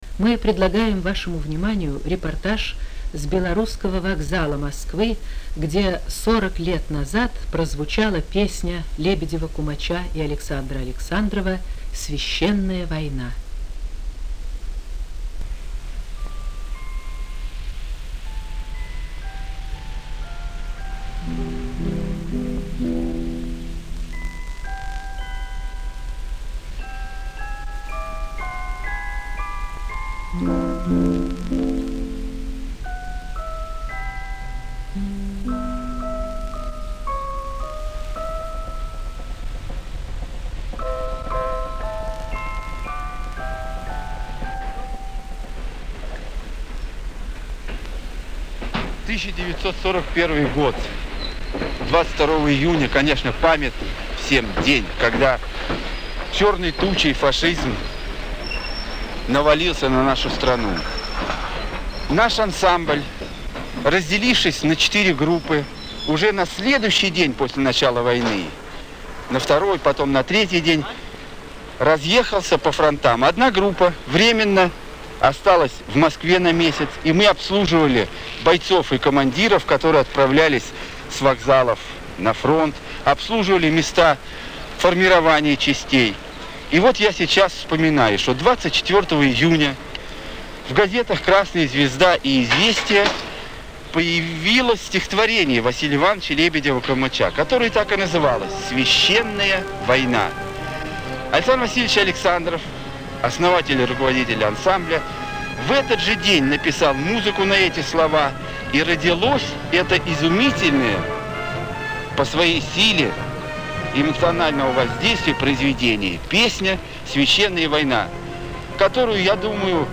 Репортаж с Белорусского вокзала Москвы. 40 лет песне "Священная война". Запись ТВ-передачи 1981 года.
Звучат воспоминания участников того памятного концерта на вокзале перед бойцами, отправлявшимися на фронт.